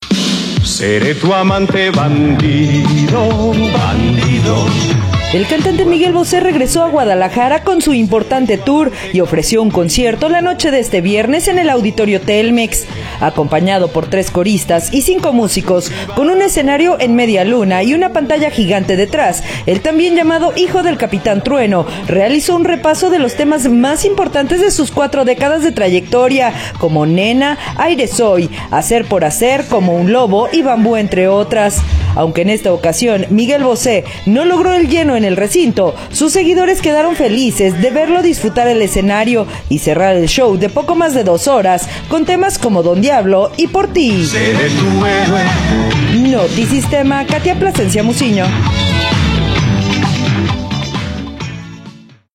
El cantante Miguel Bosé regresó a Guadalajara con su “Importante Tour” y ofreció su concierto la noche de este viernes en el auditorio Telmex.